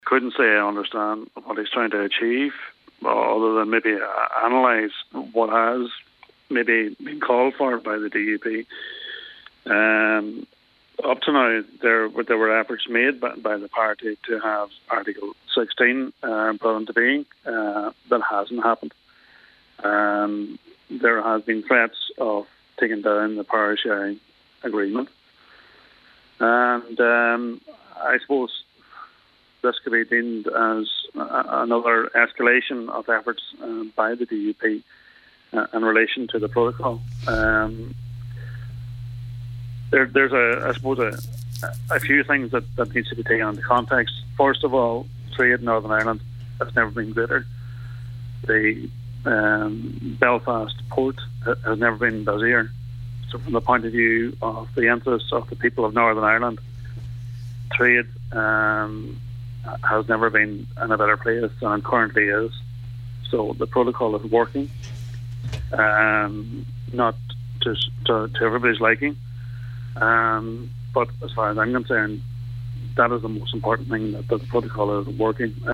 Senator Blaney says he can’t understand the decision taken as the protocol appears to be working well for the benefit of everyone: